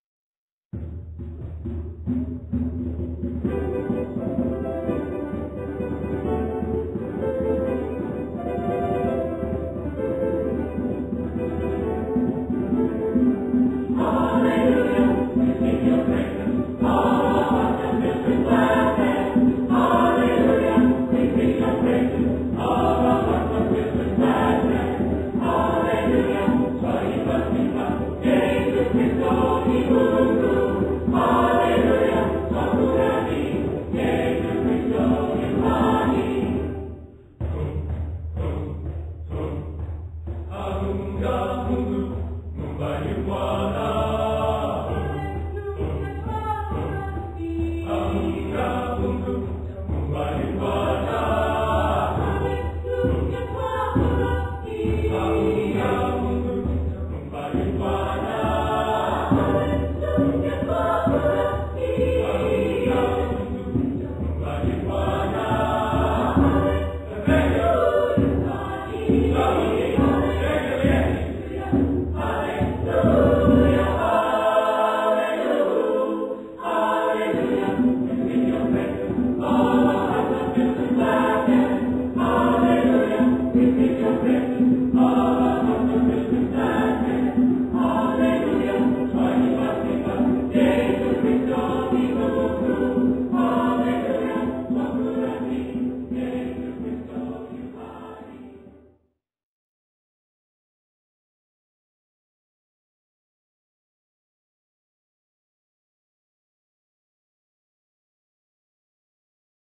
Voicing: SSATB or SSA